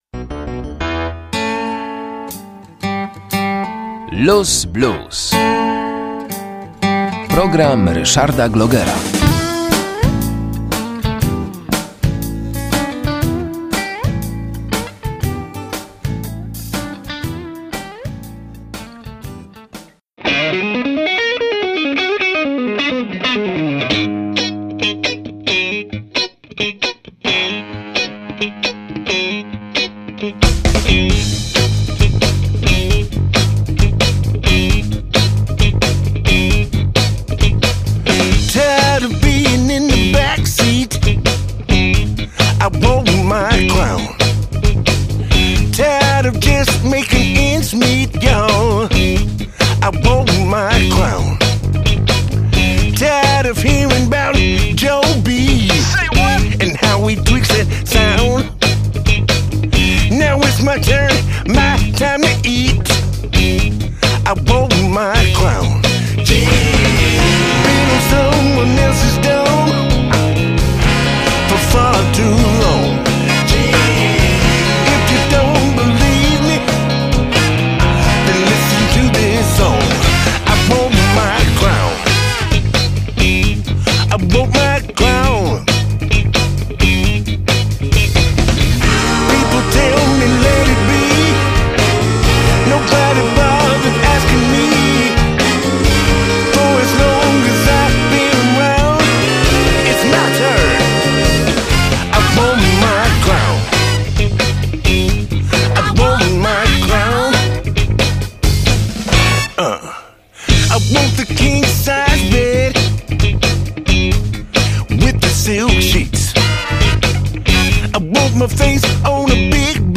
Luz Blues pozostanie bezpieczną przystanią dla wszystkich potrzebujących tego rodzaju muzyki.